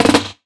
150JAMROLL-R.wav